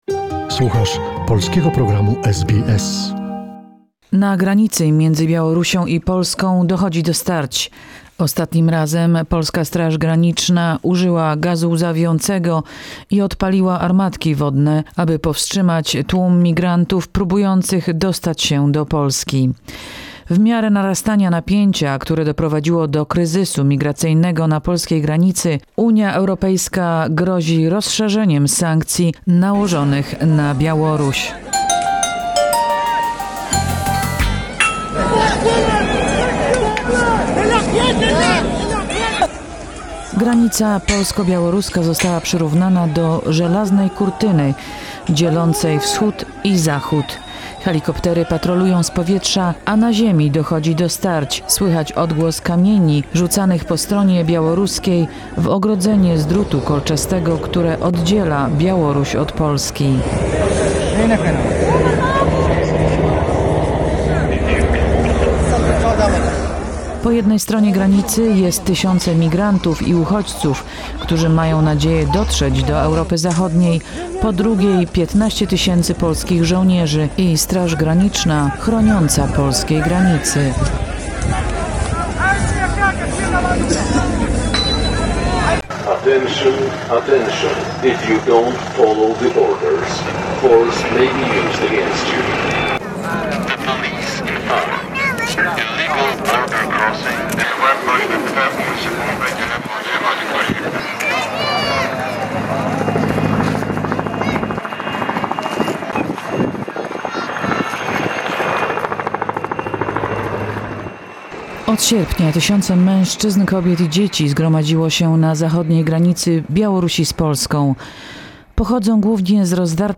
Z powietrza strefę patrolują helikoptery, na ziemi starcia, słychać odgłos kamieni rzucanych w ogrodzenie z drutu kolczastego, które oddziela Białoruś od Polski. Po jednej stronie granicy - tysiące migrantów, po drugiej 15 000 polskich żołnierzy strzeże Polskiej granicy.